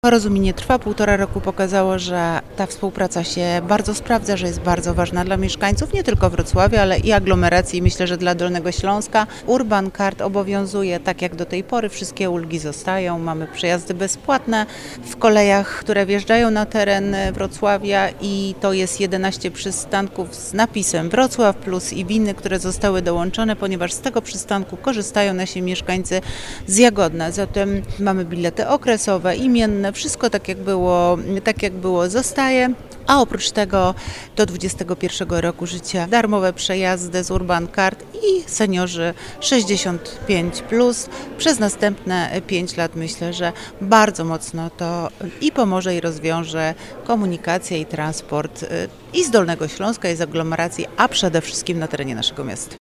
To realna oszczędność czasu i pieniędzy, z której korzystają tysiące pasażerów – podkreśla Renata Granowska, wiceprezydent Wrocławia.